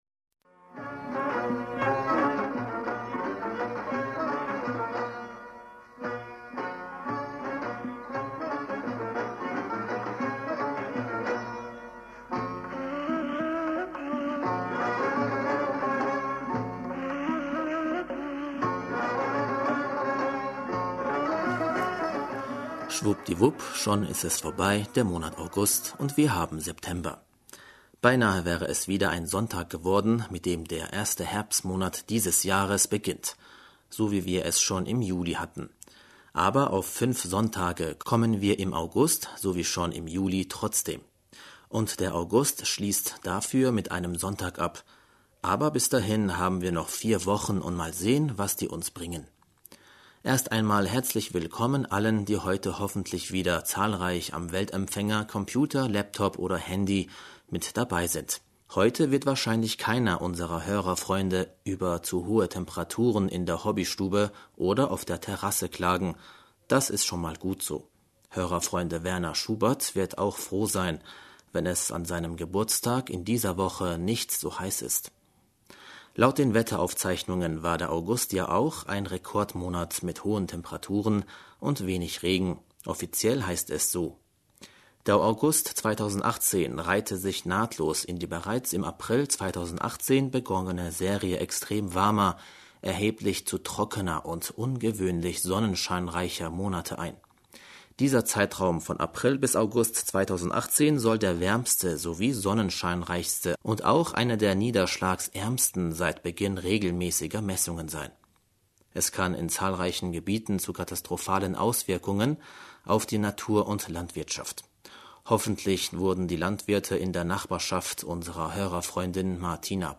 Hörerpostsendung am 2. September 2018 - Bismillaher rahmaner rahim - Schwupp·di·wupp schon ist er vorbei der Monat August und wir haben September....